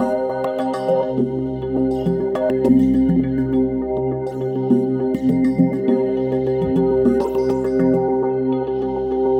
LightPercussiveAtmo5_102_C.wav